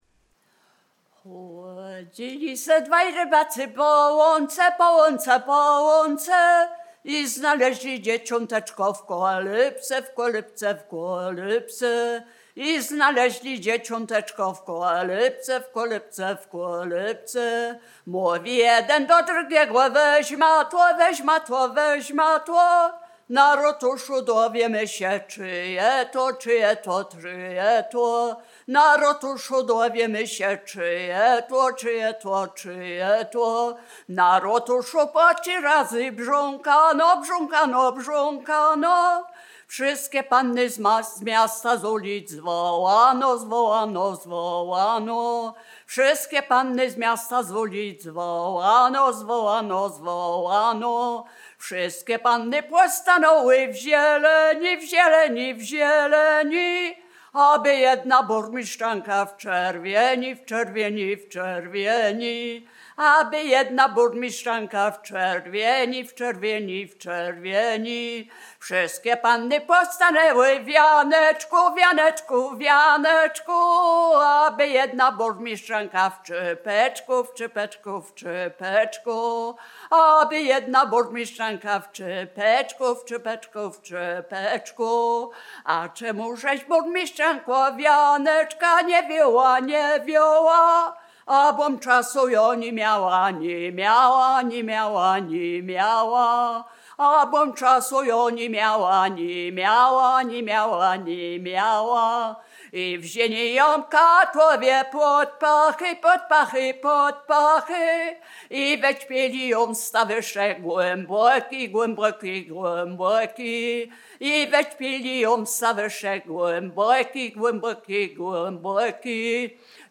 Biskupizna
Wielkopolska
Ballada
Array obyczajowe ballady